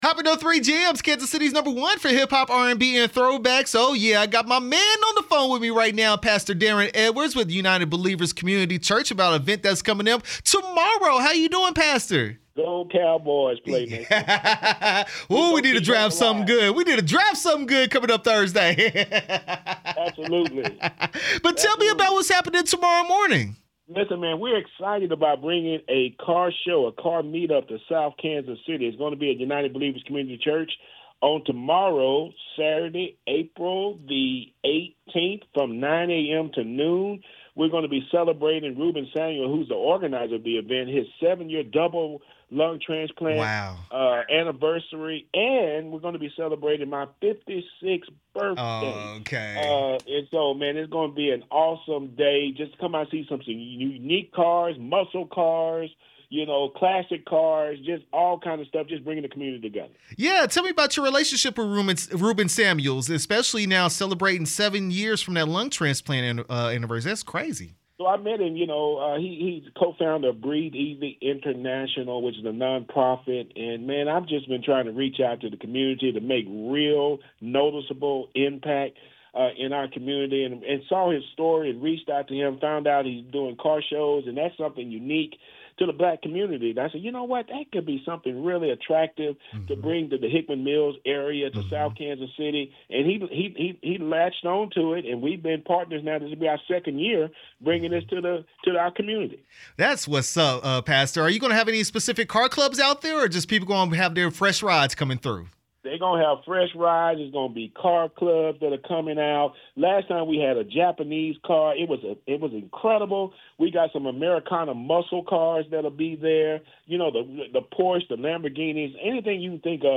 United Believers Community Church interview 4/17/26